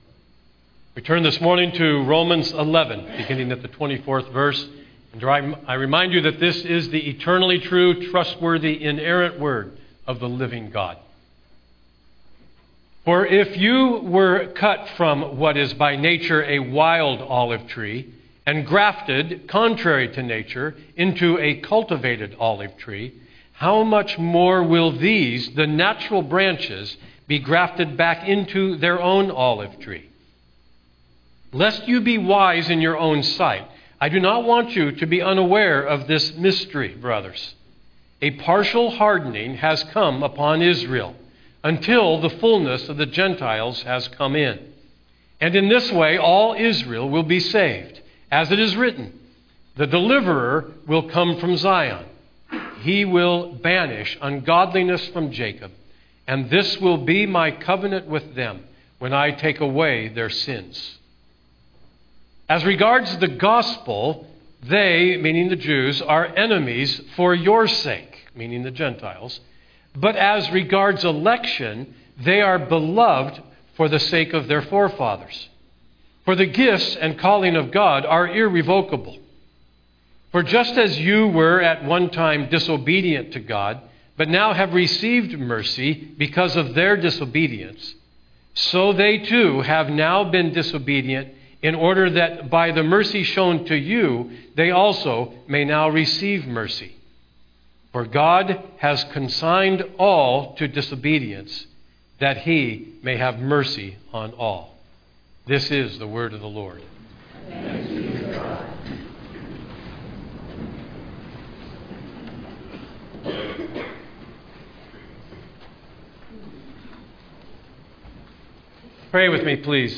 Download Sermon Notes Listen & Download Audio Series: Romans Preacher